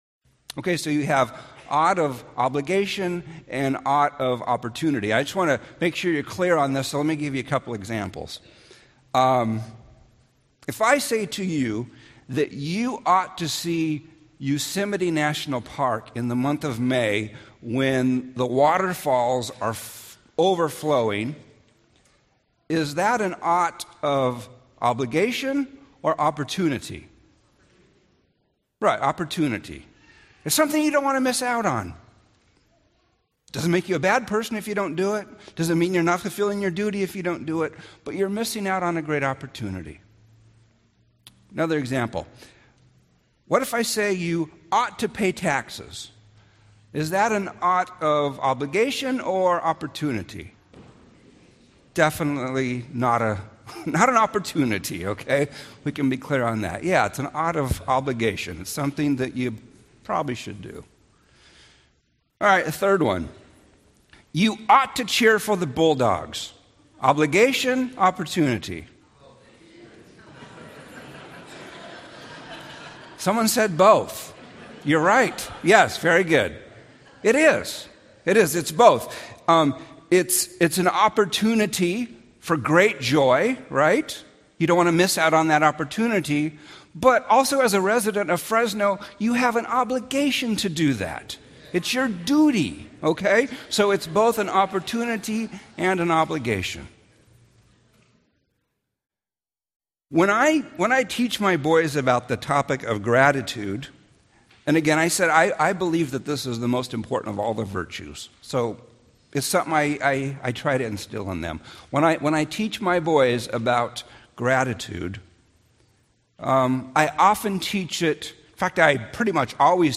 Sermons 2009